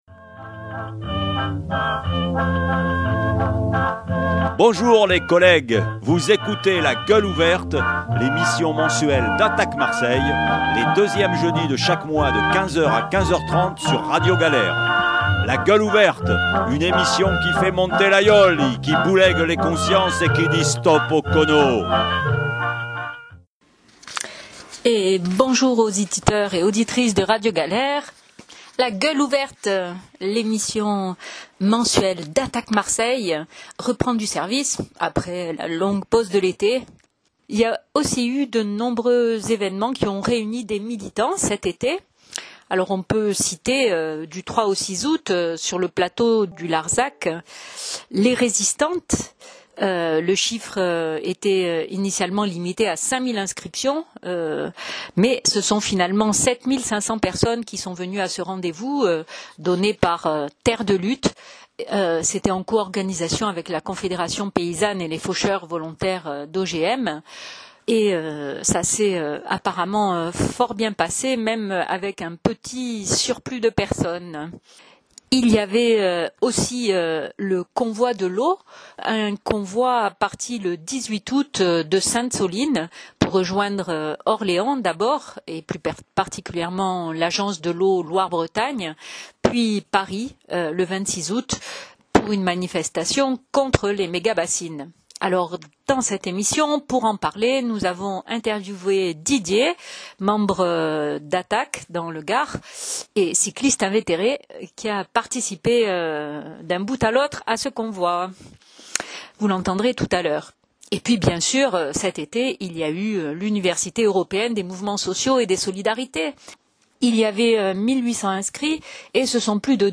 L’équipe radio reprend du service !